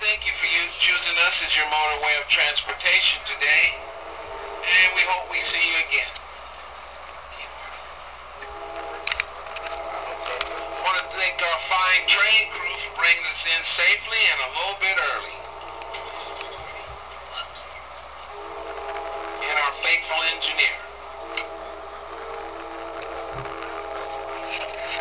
Railroad Sounds:
Amtrak Pacific Surfliner Crew says Thank you before we arrive in San Diego
thankyou_amtrak.wav